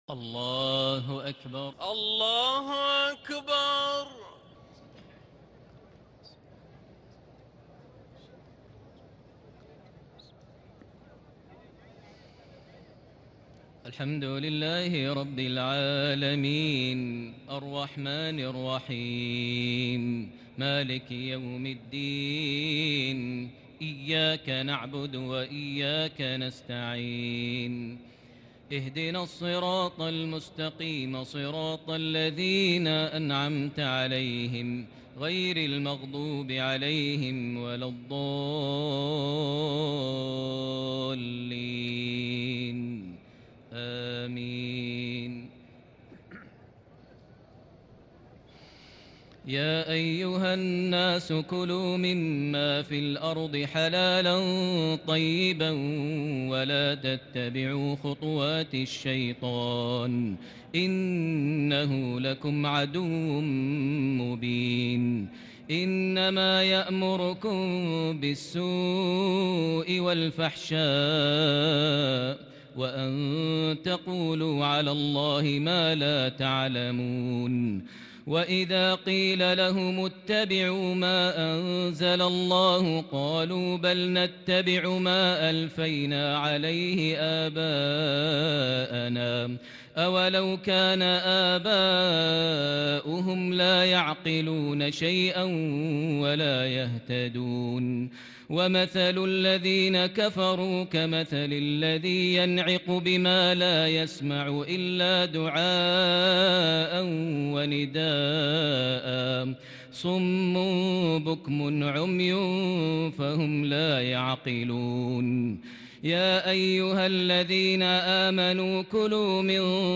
تراويح ليلة 2 رمضان 1441هـ من سورة البقرة (١٦٨ - ٢٠٣ ) Taraweeh 2st night Ramadan 1441H > تراويح الحرم المكي عام 1441 🕋 > التراويح - تلاوات الحرمين